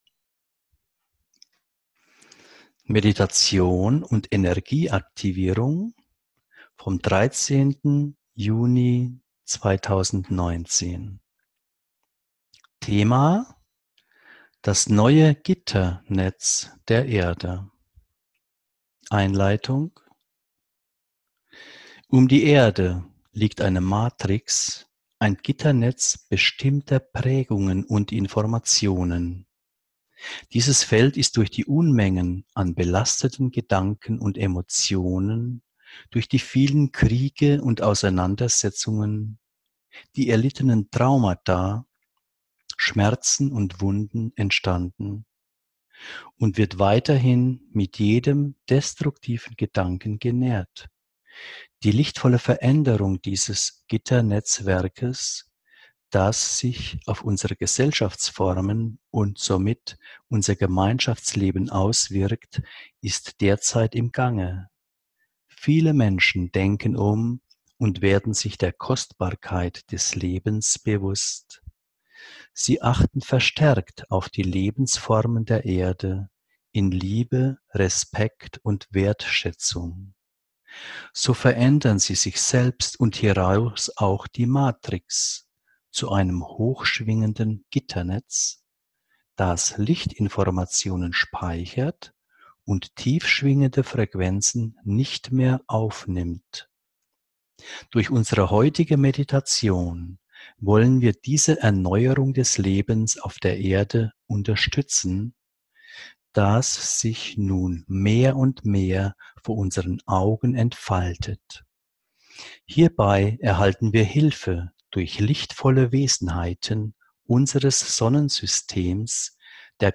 Meditation: Das neue Gitternetz der Erde